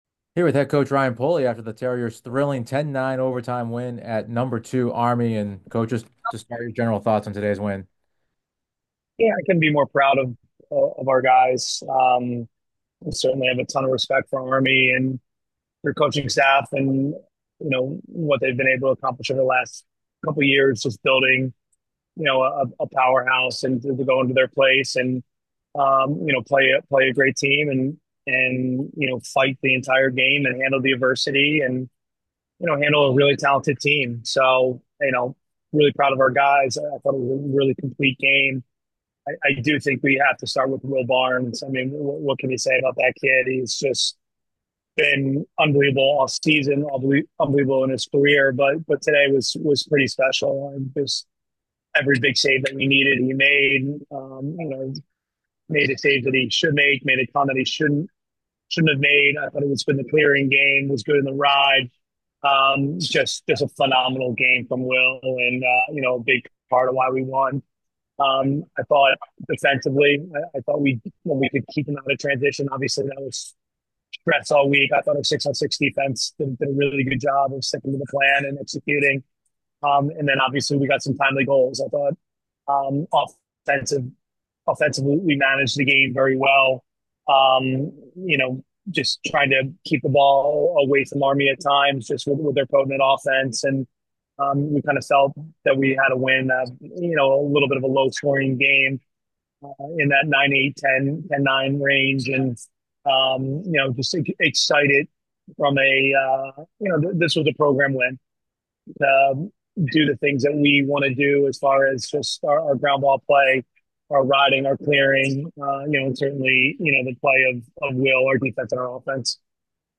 Army West Point Postgame Interview